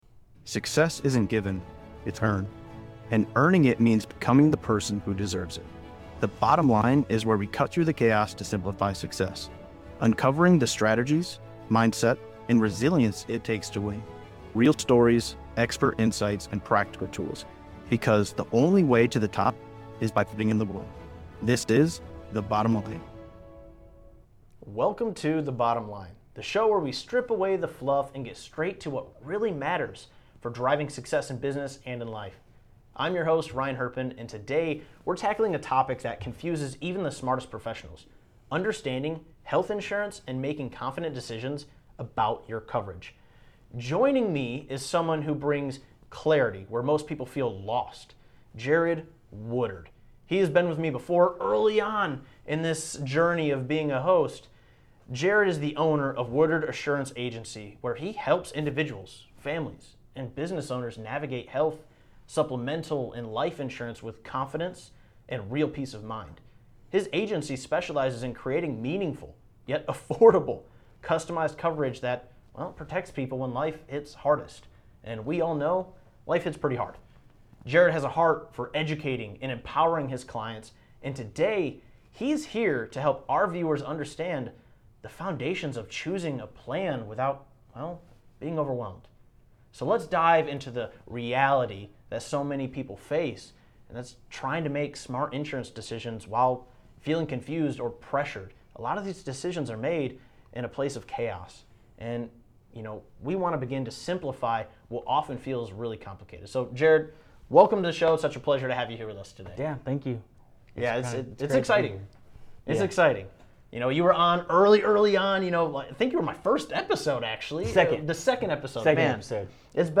Learn how small and mid-sized business owners can offer affordable benefits, reduce employee turnover, protect against rising medical costs, and create long-term financial security for both employees and families. A must-watch conversation about coverage gaps, medical bills, and building peace of mind before crisis hits.